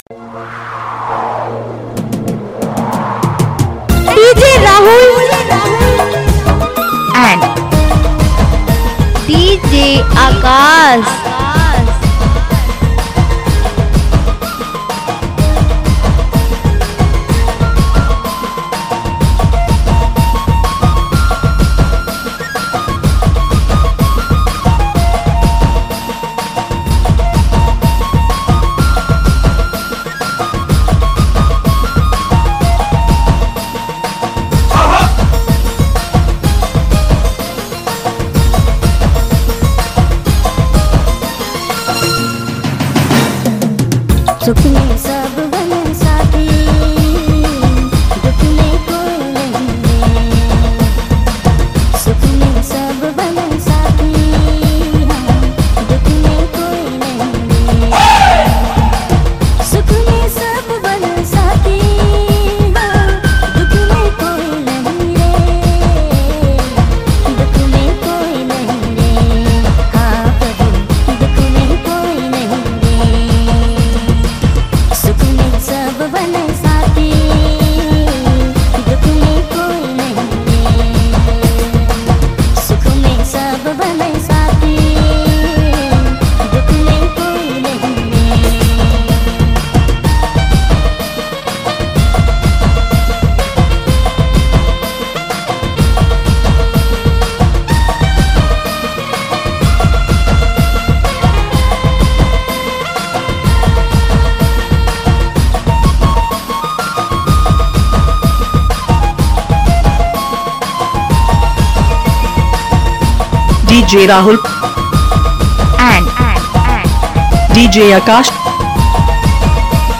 Dj Remixer
Christmas Dhanka Mix Song